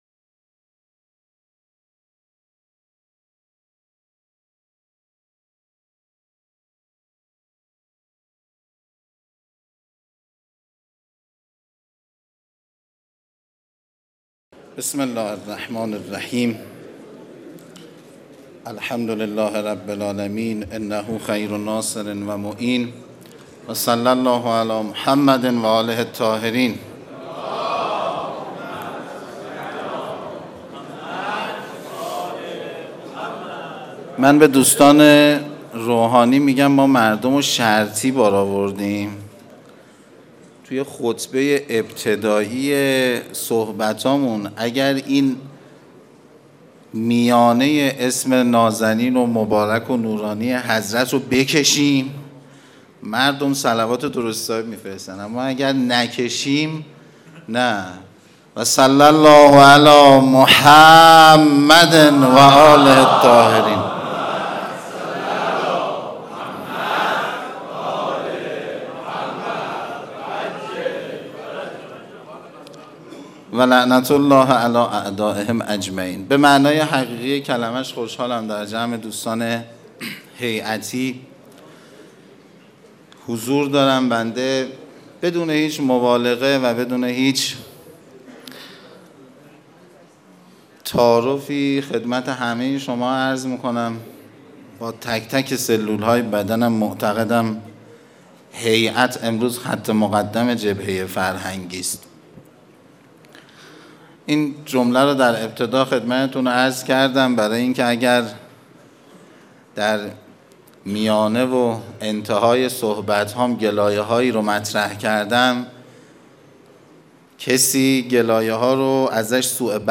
سخنرانی | لزوم توجه جدی به موضوع بحران جمعیت
دومین همایش ملی هیأت های محوری و برگزیده کشور